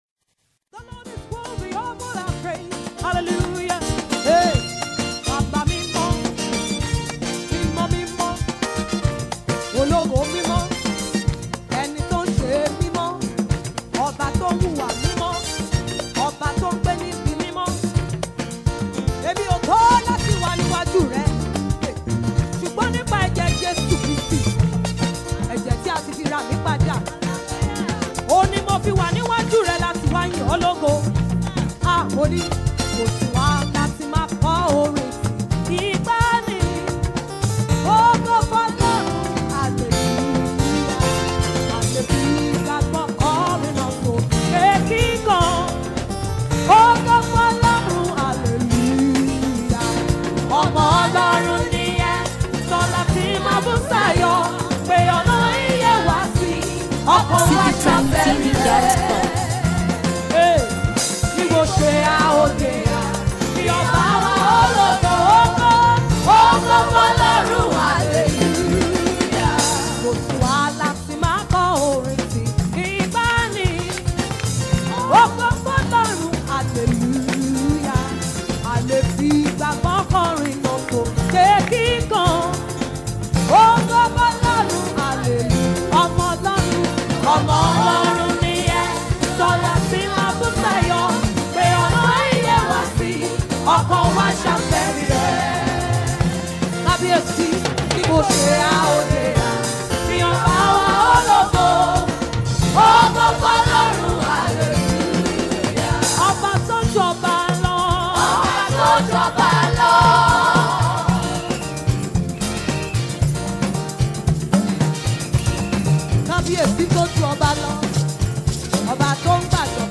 a powerful and magnificent praise melody